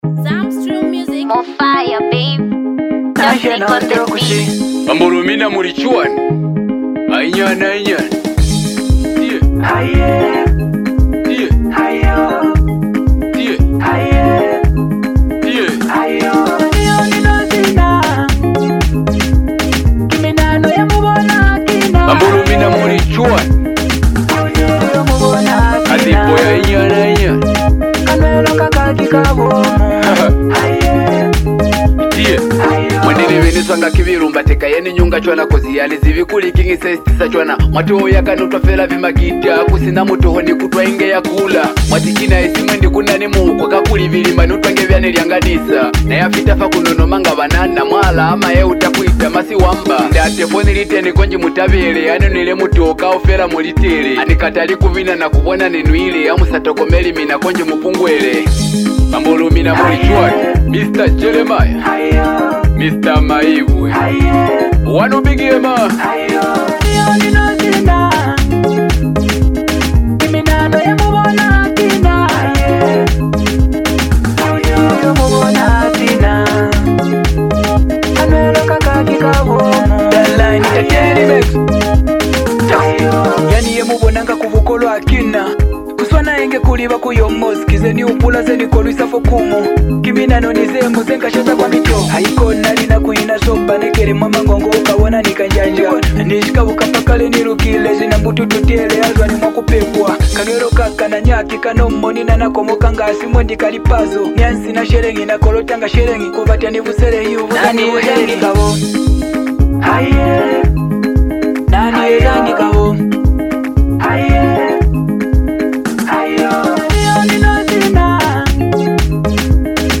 This EP blends emotion, culture, and smooth melodies